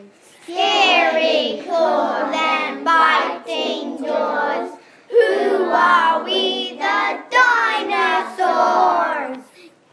Poem